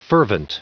Prononciation du mot fervent en anglais (fichier audio)
Prononciation du mot : fervent